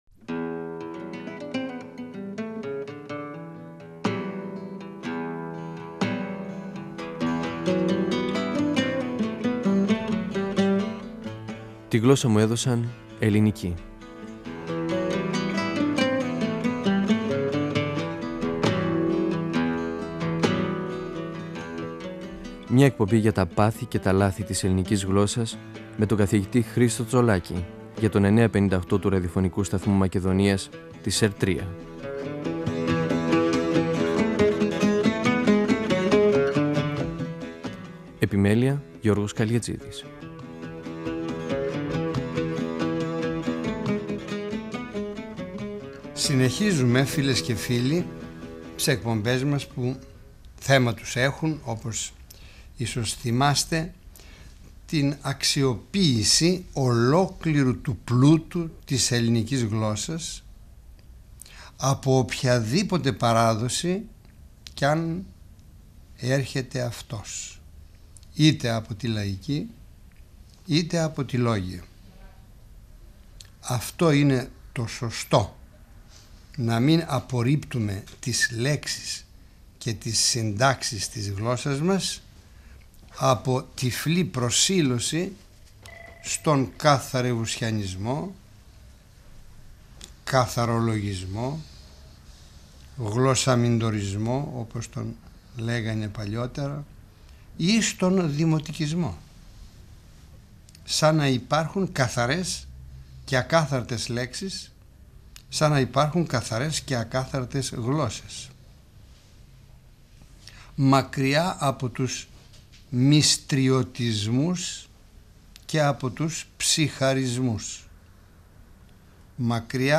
Ο γλωσσολόγος Χρίστος Τσολάκης (1935-2012) μιλά για τον πλούτο της ελληνικής γλώσσας που έρχεται από την παράδοση και τη συνύπαρξη λαϊκών και λόγιων λέξεων.
Νησίδες & 9.58fm, 1999 (πρώτος, δεύτερος, τρίτος τόμος), 2006 (τέταρτος τόμος, πέμπτος τόμος). 958FM Αρχειο Φωνες Τη Γλωσσα μου Εδωσαν Ελληνικη "Φωνές" από το Ραδιοφωνικό Αρχείο Εκπομπές ΕΡΤ3